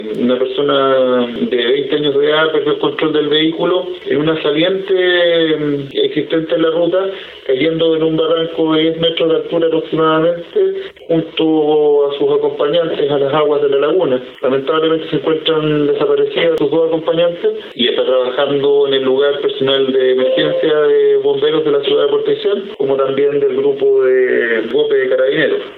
Así lo señaló el fiscal de Puerto Aysén, Pedro Poblete.